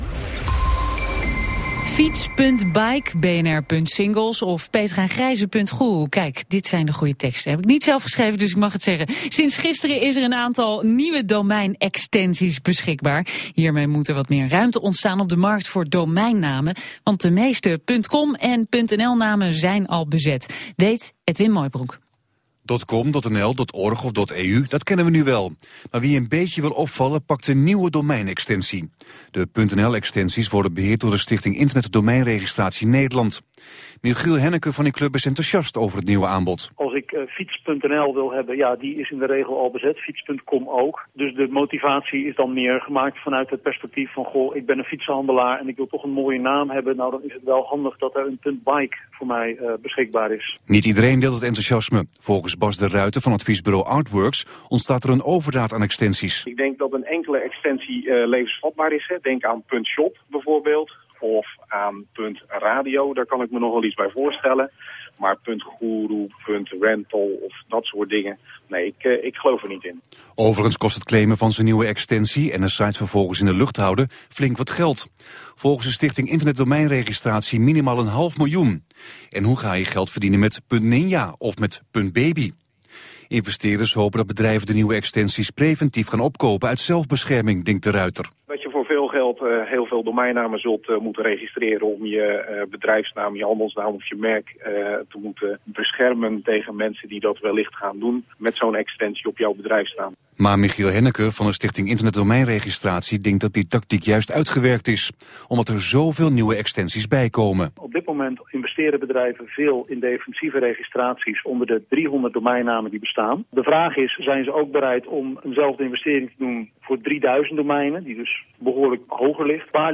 Gesprek over de nieuwe domeinnaamextensies